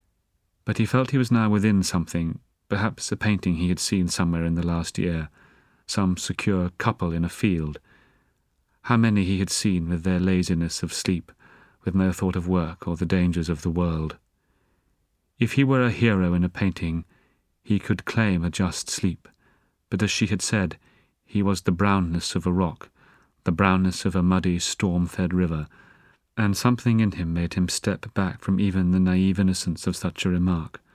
【有声英语文学名著】英国病人 46 听力文件下载—在线英语听力室